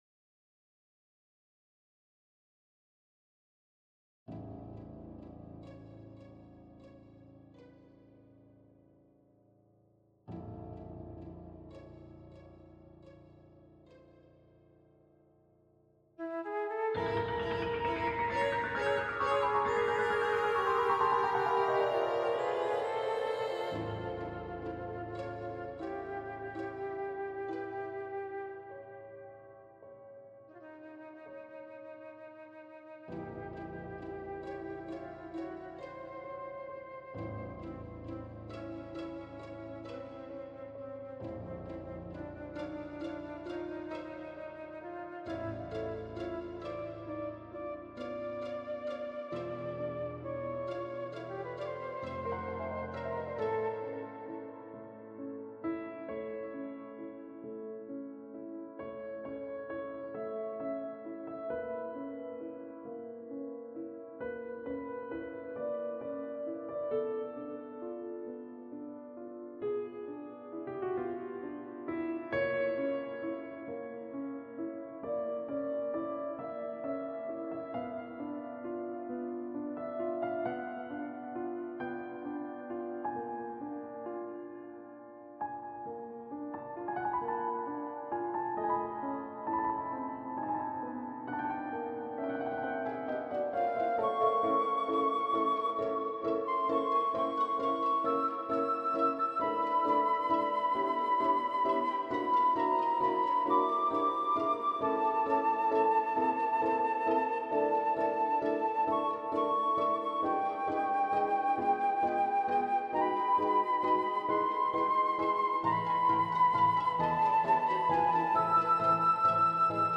Do NOT expect anything bombastic though. It's a trio for flute, piano and violin so it's 3 instruments.